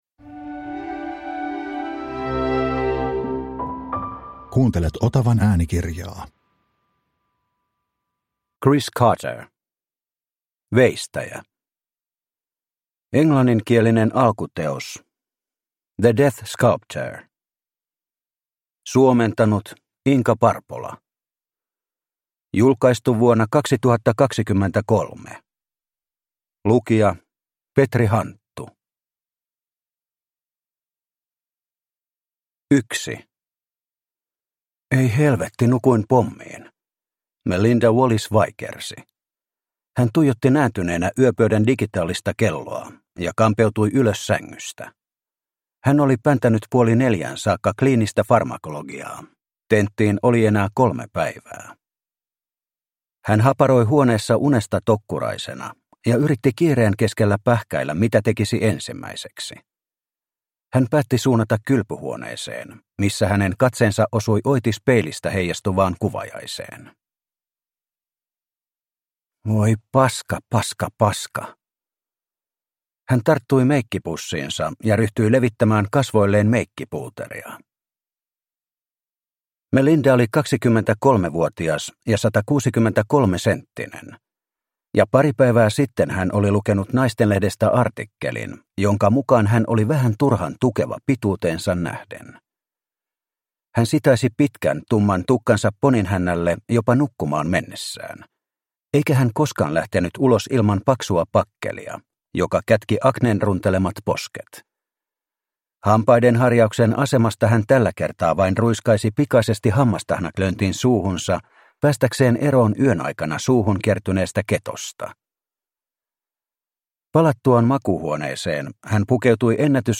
Veistäjä – Ljudbok – Laddas ner